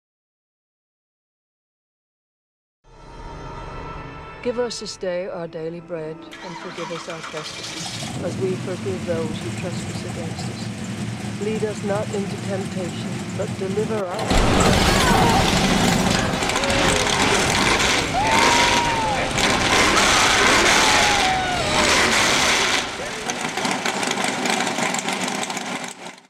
We agree with you, the sound effects free download By quicktimeperformance 0 Downloads 9 months ago 26 seconds quicktimeperformance Sound Effects About We agree with you, the Mp3 Sound Effect We agree with you, the neighborhood is a little too quiet! Change that with a set of our electric cutouts!